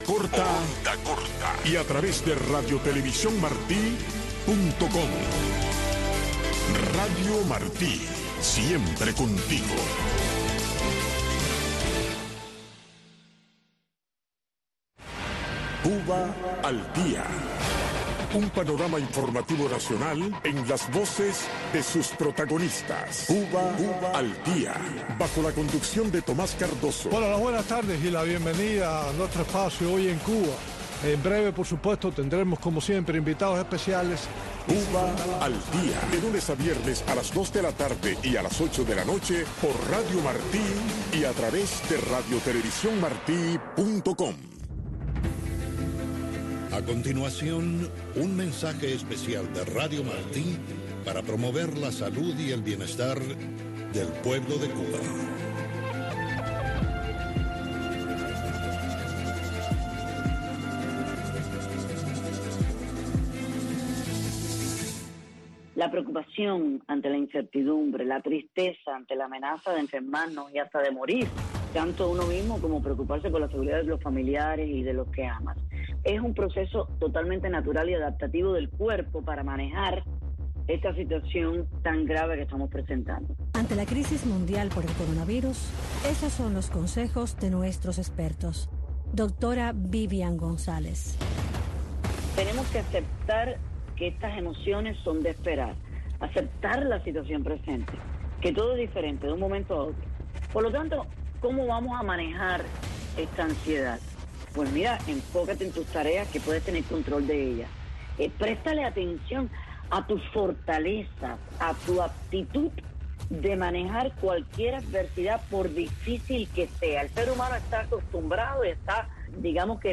una revista de entrevistas